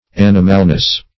\An"i*mal*ness\